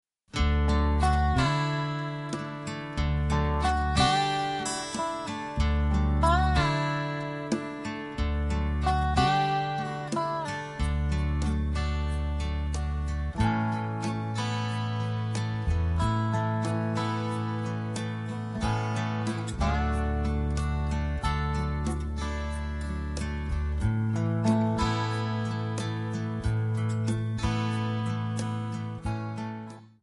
Backing track Karaoke